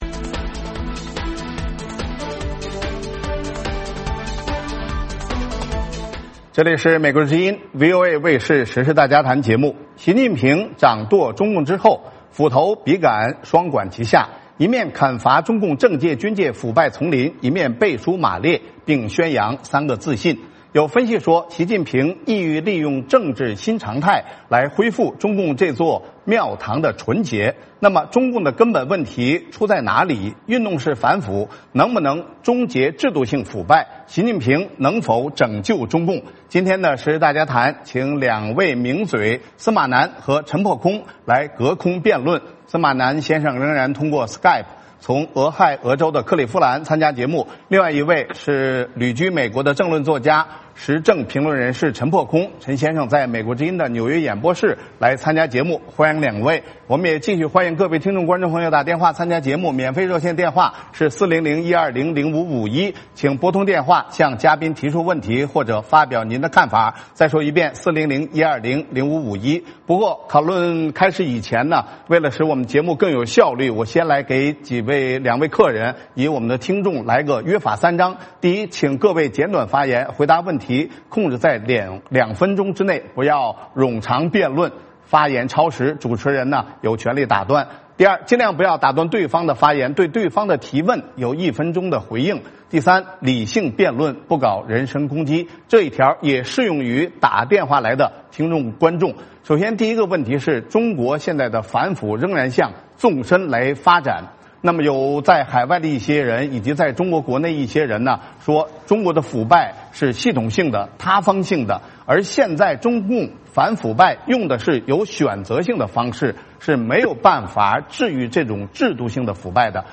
今天的时事大家谈请两位名嘴司马南和陈破空来隔空辩论。